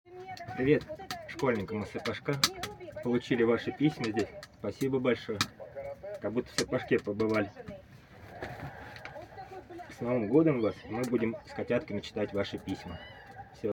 Тем не менее, мы хотим поделиться звуковой дорожкой этого видео и рассказать, что оно записано в землянке на передовой, на нем видно, что полученные от ребят письма прочитаны и даже перечитываются по нескольку раз.